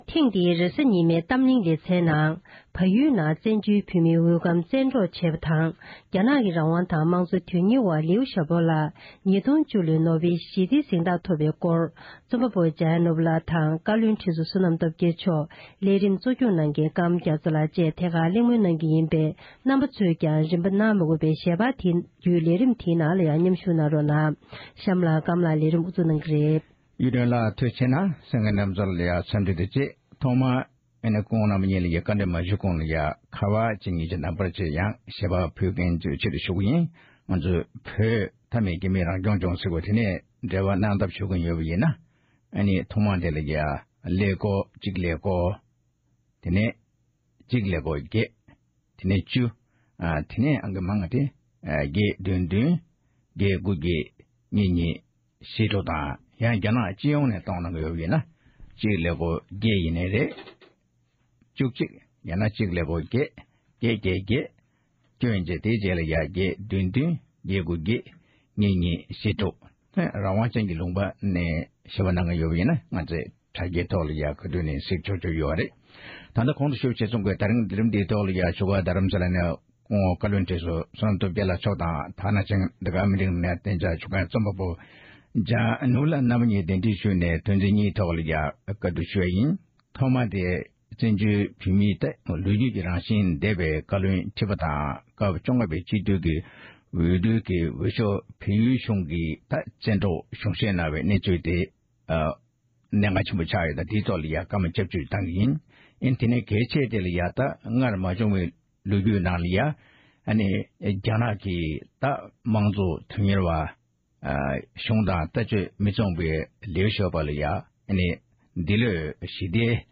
གཏམ་གླེང
གླེང་མོལ